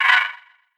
Hit (Yonkers).wav